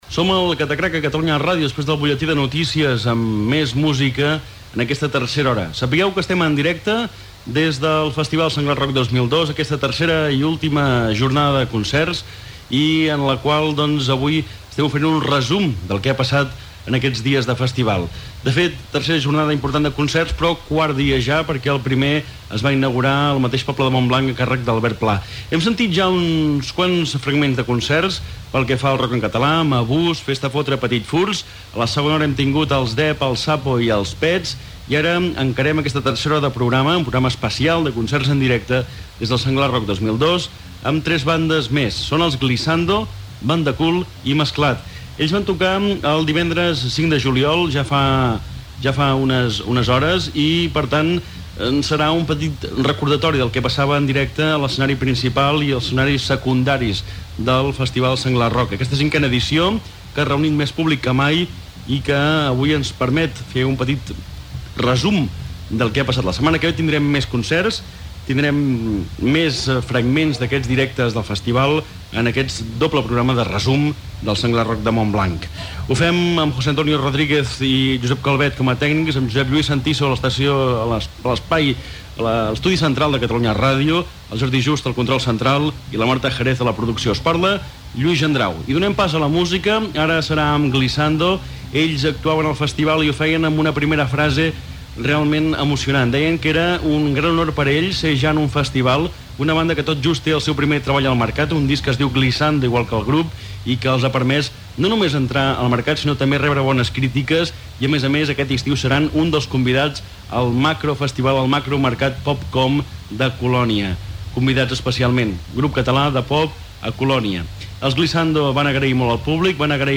Espai fet des del festival Senglar Rock 2002.
Musical